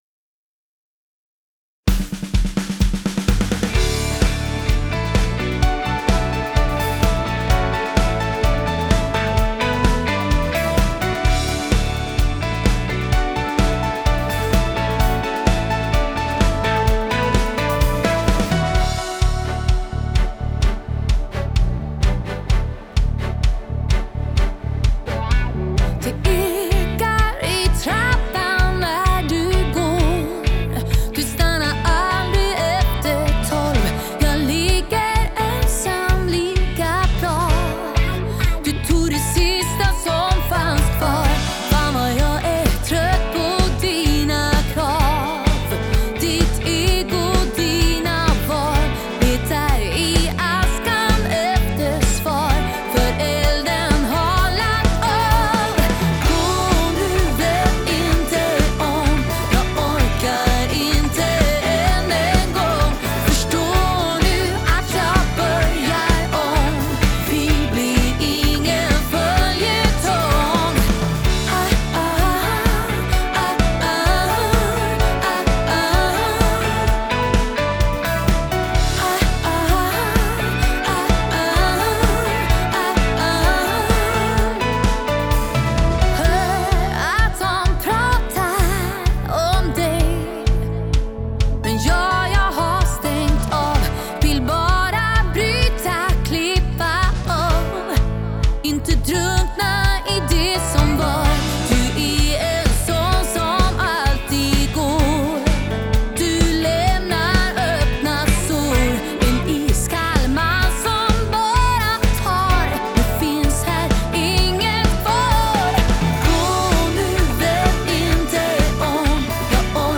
Allt inom pop, rock, disco och soul.
• Coverband
• Popband